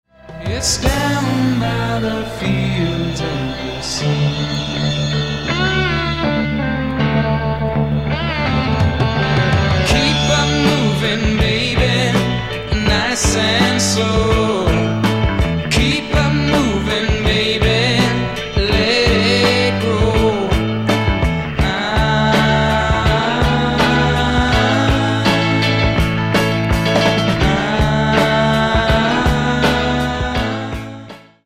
bass, vocals
drums, percussion
Album Notes: Recorded at Can-Base Studios, Vancouver, Canada